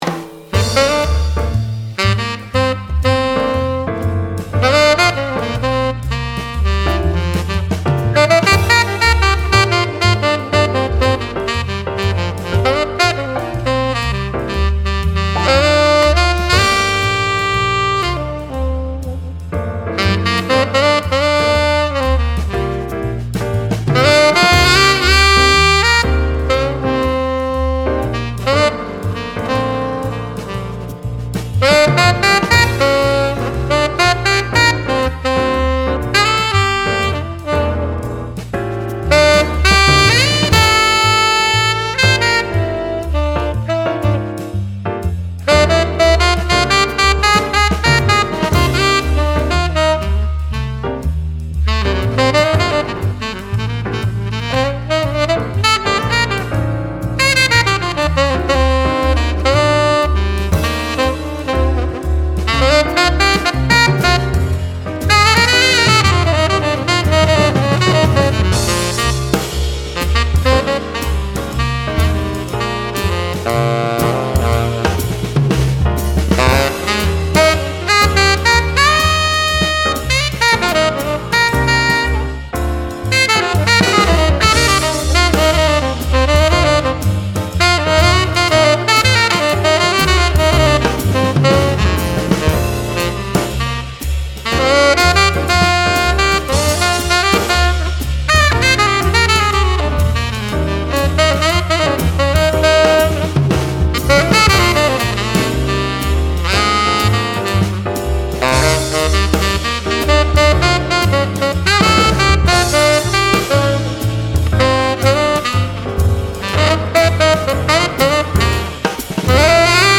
音色と特徴ダークで音が太い、輪郭のある音。
Tenor